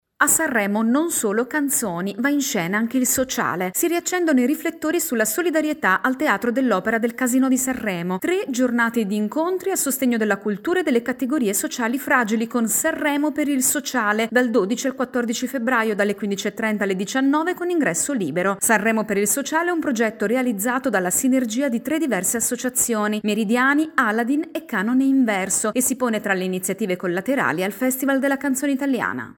Al fianco del Festival della Canzone Italiana, la rassegna di dibattiti a sostegno della cultura. Il servizio